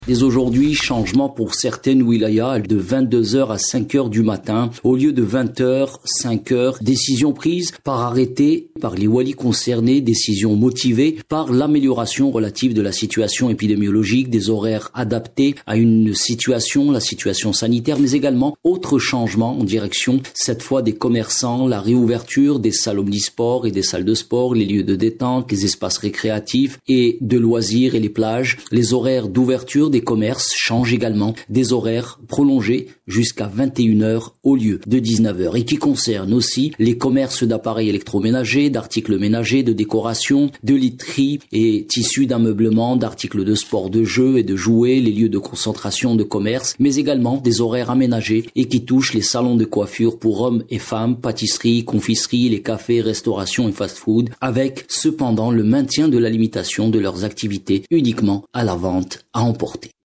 Compte rendu